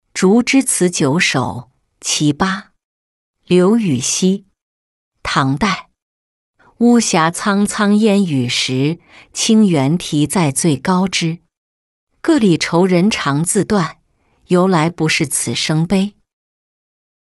竹枝词九首·其八-音频朗读